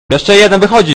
Worms speechbanks
hurry.wav